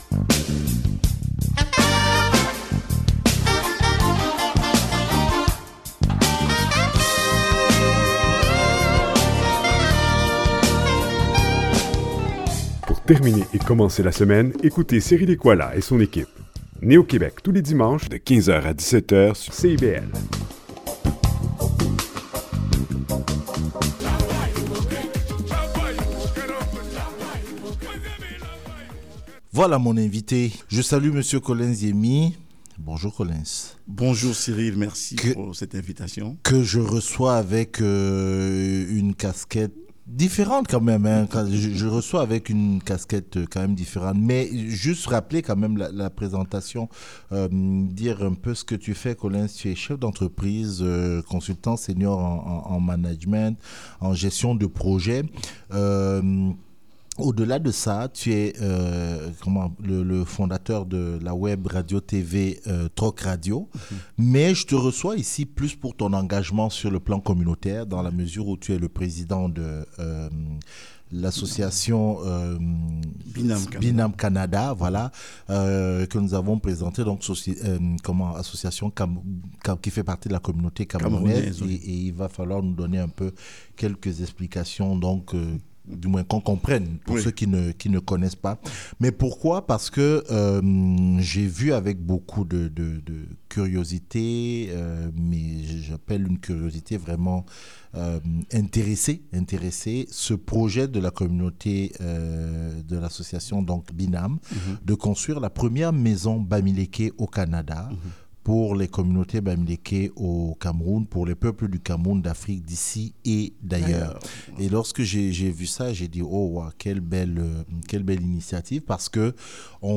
Une façon de dire : voilà ce que nous sommes, ce que nous apportons au Québec, au Canada, au monde « , affirme-t-il dans une entrevue accordée à NéoQuébec.